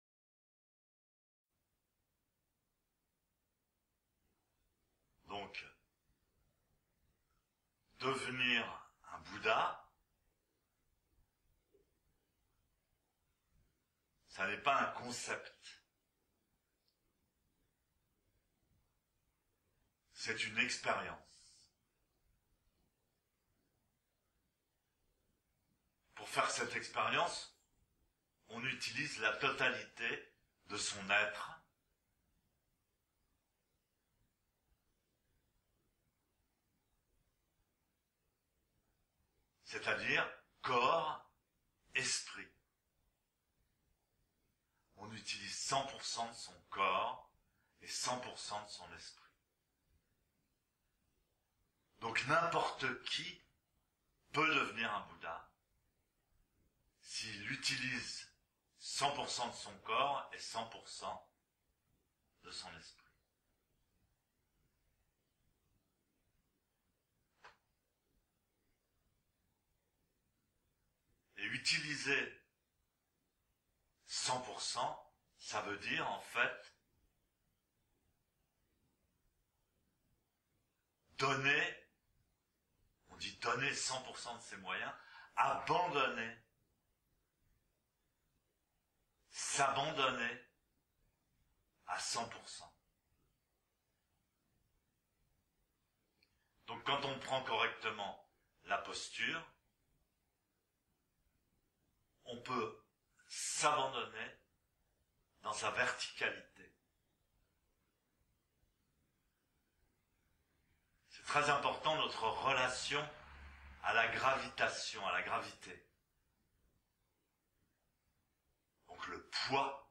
eka et bodhidharma 3 contes zen 1